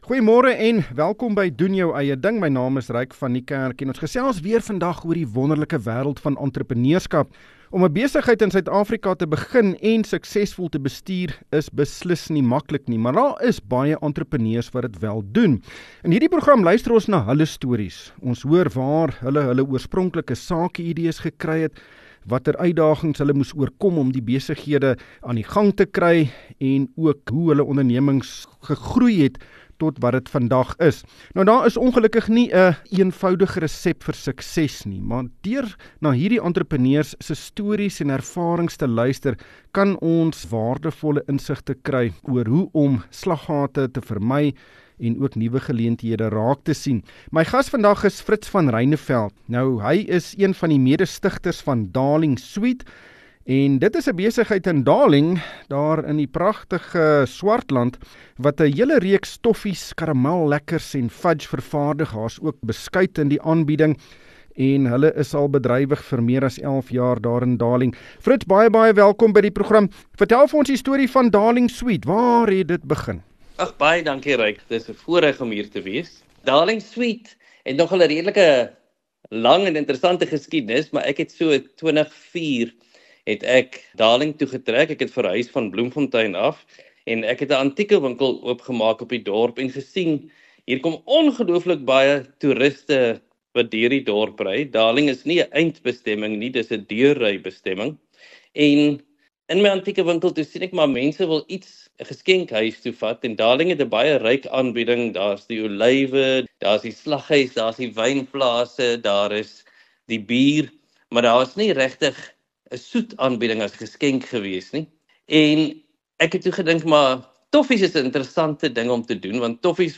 Wees deel van die gesprek met beleggingskenners en finansiële gurus in ateljee.